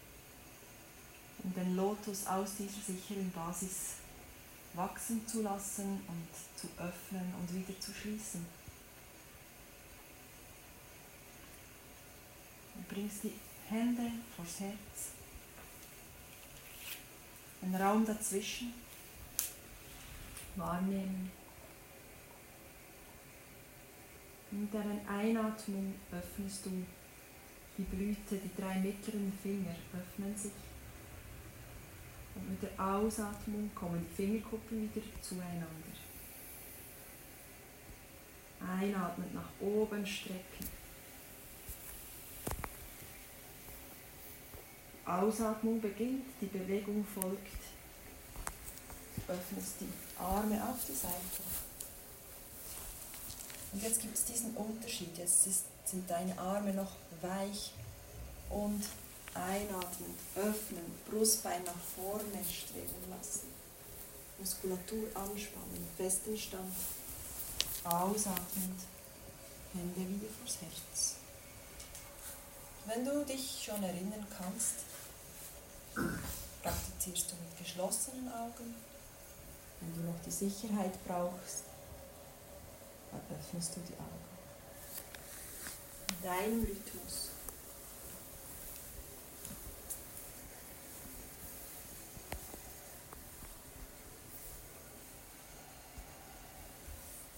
Hier geht's zur Yogaübung "Lotus"